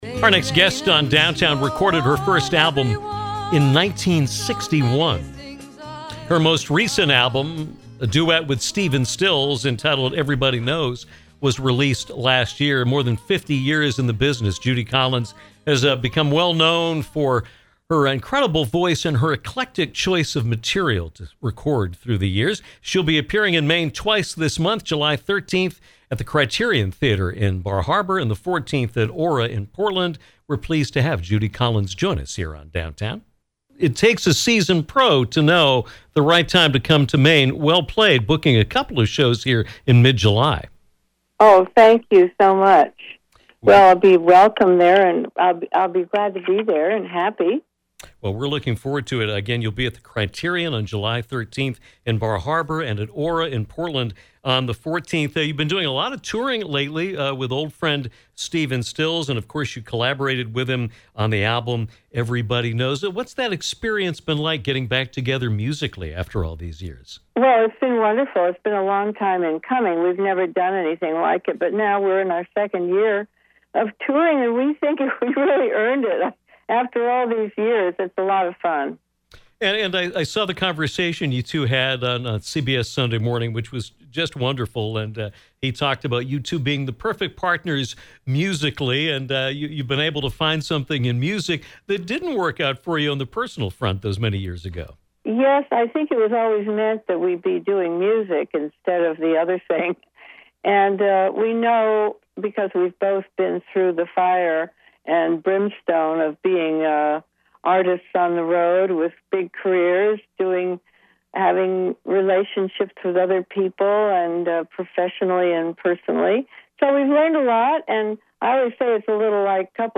Legendary singer Judy Collins joined Downtown to talk about her career, the recent collaboration with Stephen Stills, and her upcoming performances in Maine, July 13 at the Criterion Theatre in Bar Harbor and July 14 at Aura in Portland.